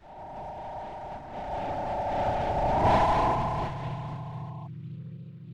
moan5.ogg